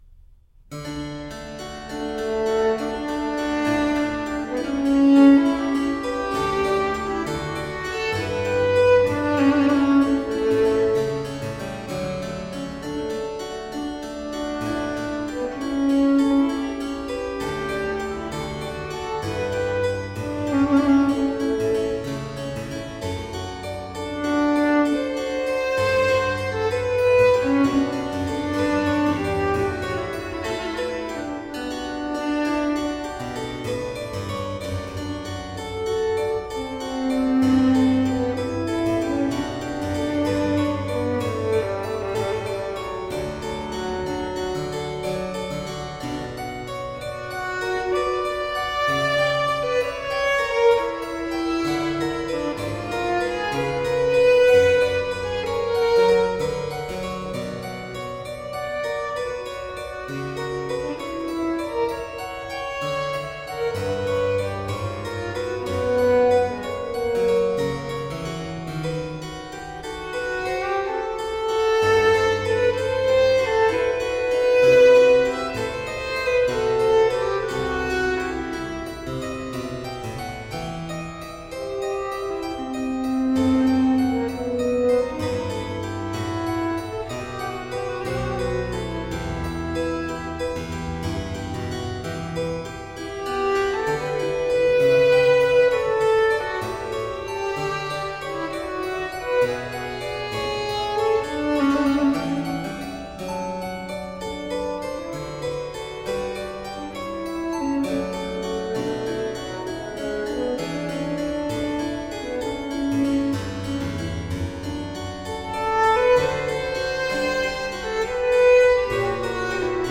Lilting renaissance & baroque vocal interpretations .
Classical, Chamber Music, Baroque, Instrumental, Cello